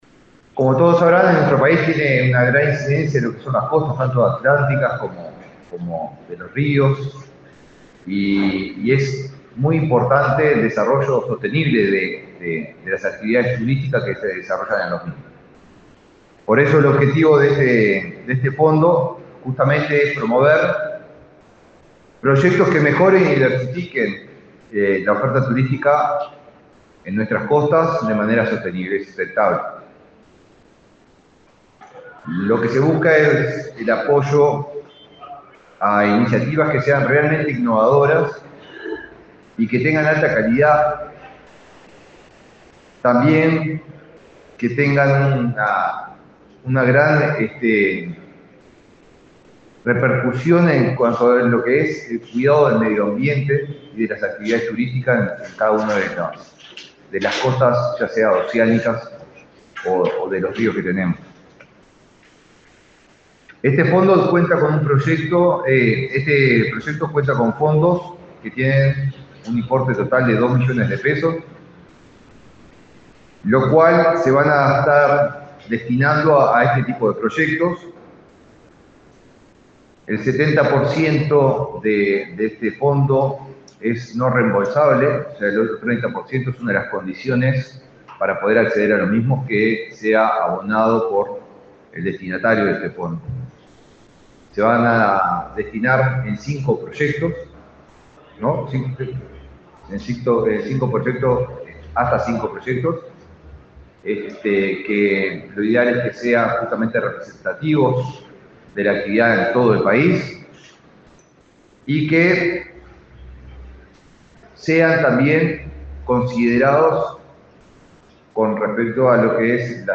Palabras del ministro de Turismo, Eduardo Sanguinetti
Palabras del ministro de Turismo, Eduardo Sanguinetti 31/07/2024 Compartir Facebook X Copiar enlace WhatsApp LinkedIn El ministro de Turismo, Eduardo Sanguinetti, participó, este miércoles 31 en Montevideo, en la presentación del llamado titulado Fondo para el Desarrollo Costero Sostenible.